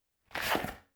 Keep Weapon Sound.wav